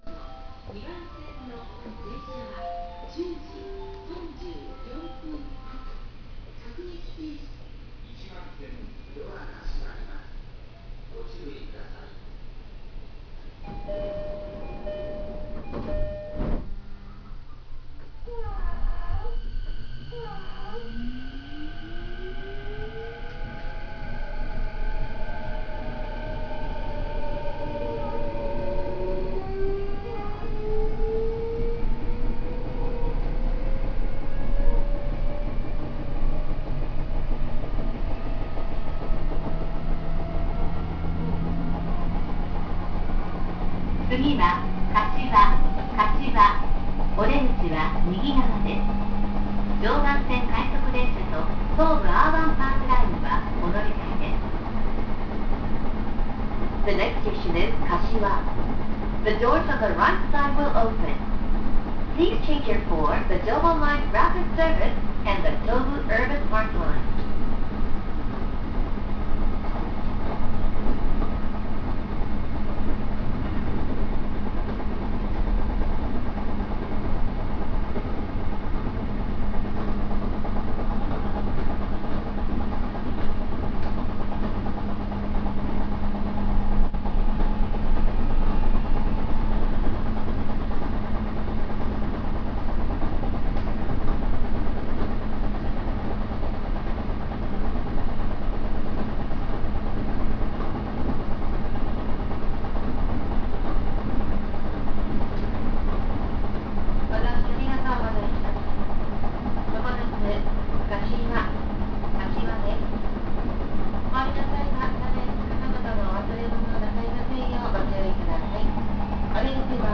・4000形走行音
【JR常磐線】南柏〜柏（2分40秒：871KB）
起動音こそE233系に似ていますが、一度変調すると全く違う音になります（三菱のIGBTである事自体は同様）。類似する走行音を持つ車両に名鉄4000系があります。